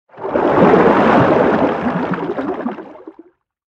File:Sfx creature jellyfish swim os 05.ogg - Subnautica Wiki
Sfx_creature_jellyfish_swim_os_05.ogg